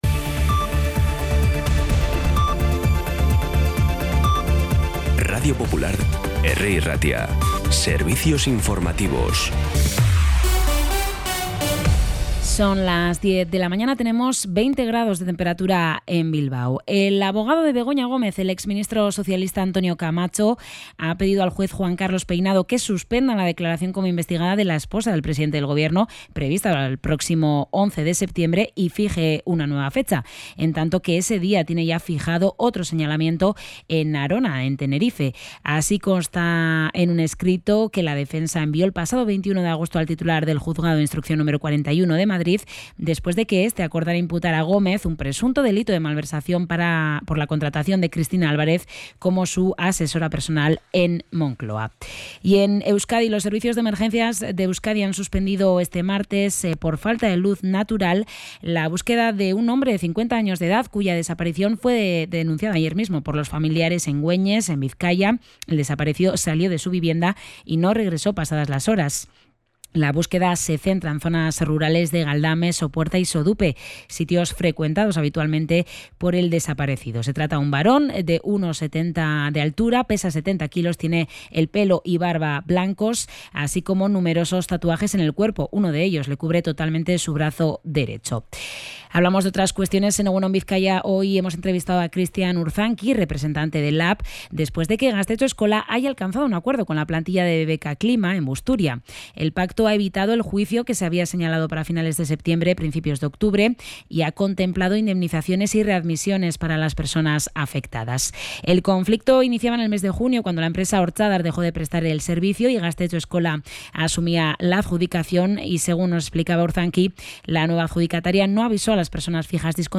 Los titulares actualizados con las voces del día.